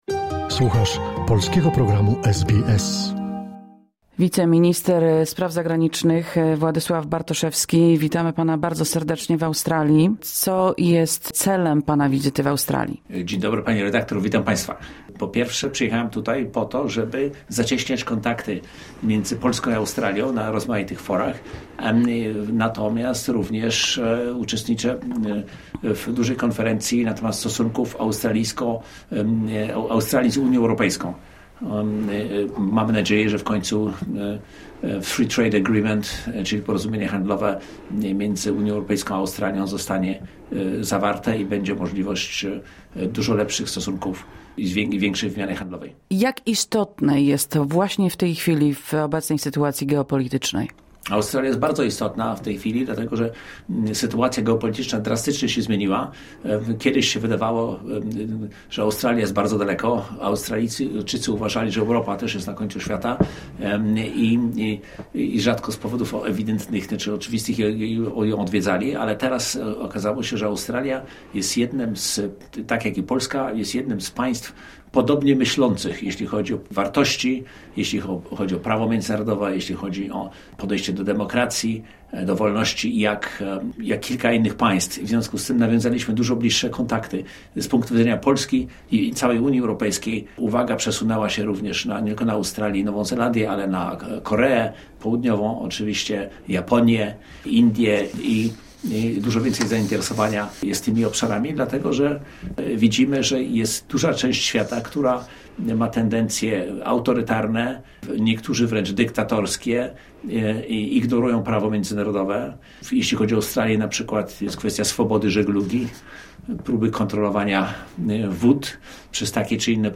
Wiceminister Spraw Zagranicznych Polski Władysław Bartoszewski w studio SBS
Polish Deputy Minister of Foreign Affairs Wladyslaw Bartoszewski, Sydney, SBS Studios, 1 April 2025.